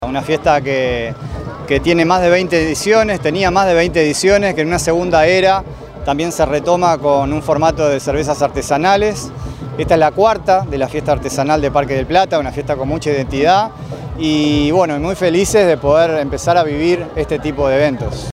Fiesta de la Cerveza Artesanal en Parque del Plata
sergio_machin_-_director_general_de_cultura.mp3